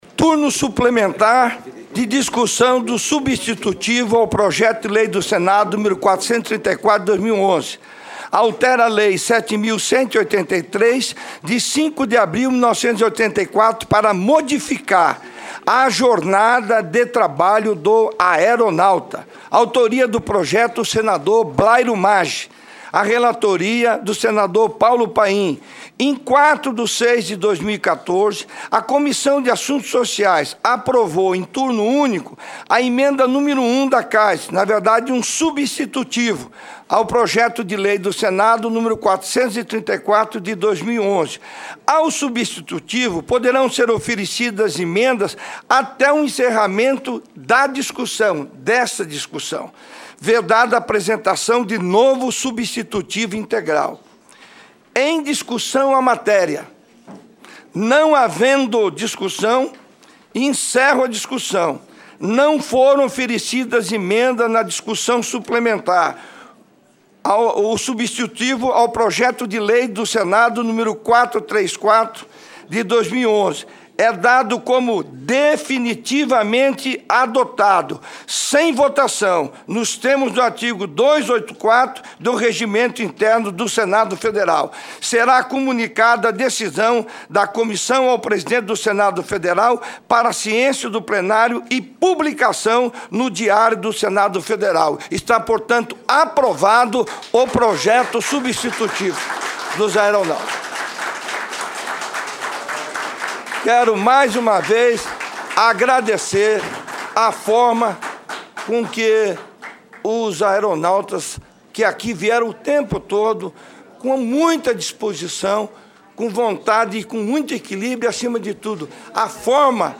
Como houve acordo para aprovação do texto, a matéria já pode ser avaliada pelo Plenário da Casa ainda na tarde desta quarta. Acompanhe a entrevista concedida por Paim ao Programa Conexão.